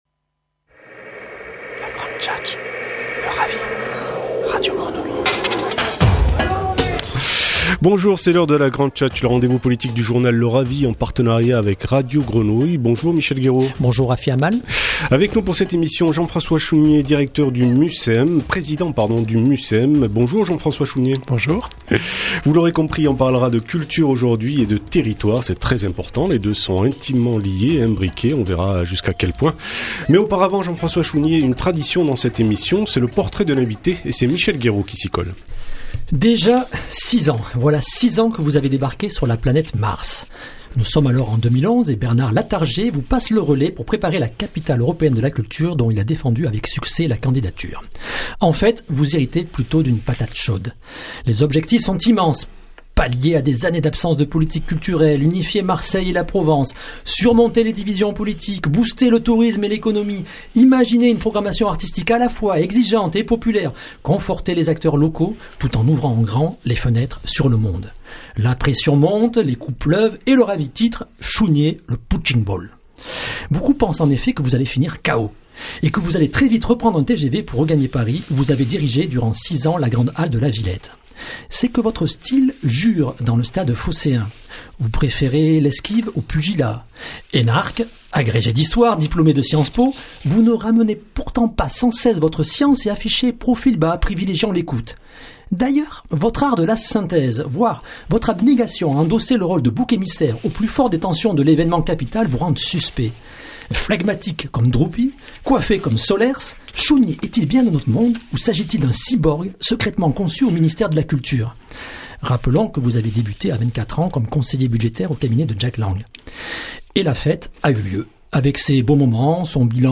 Entretien radio en partenariat avec Radio Grenouille